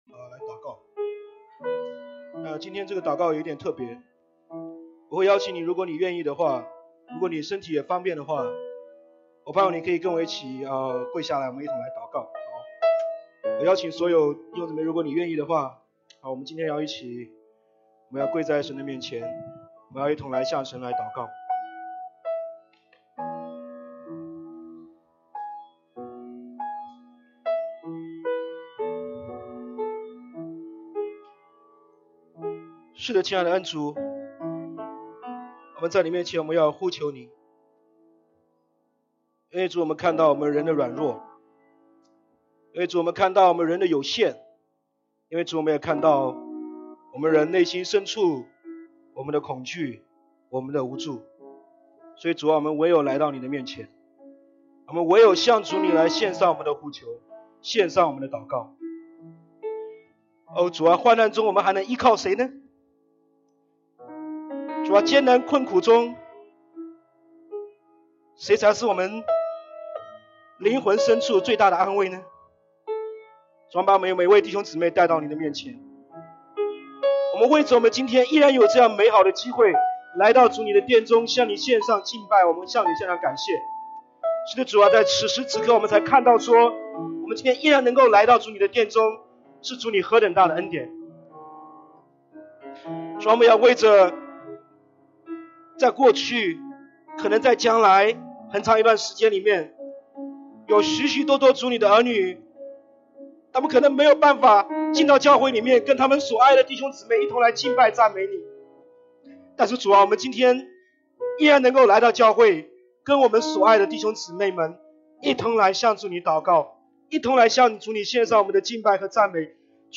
要儆醒！Stay Awake | 02/09/2020 主日证道